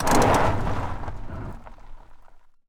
car-brakes-2.ogg